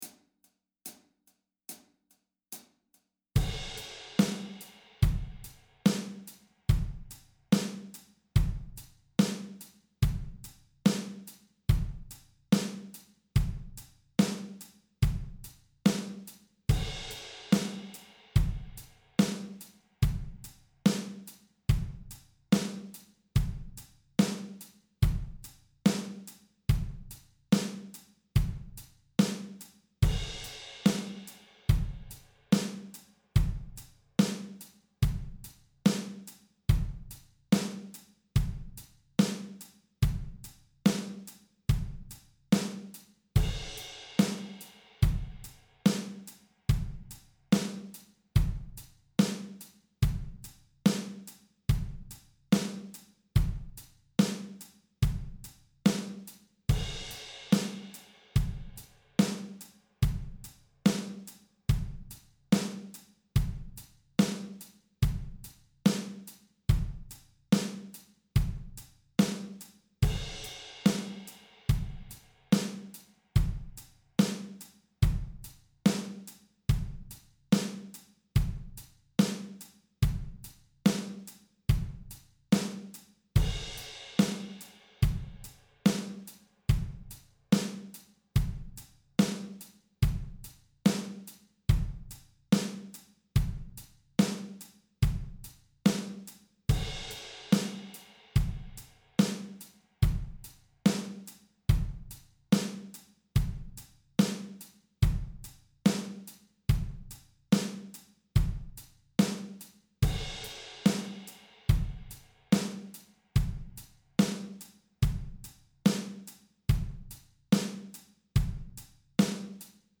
Drum Tracks for Extra Practice
With that in mind, I put together some basic bass drum & snare combos that evoke the AC/DC sound.
Slowest (72bpm) - download, or press the play button below to stream:
584-drums-72bpm.mp3